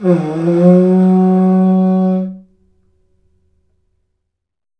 Index of /90_sSampleCDs/NorthStar - Global Instruments VOL-1/WND_AfrIvoryHorn/WND_AfrIvoryHorn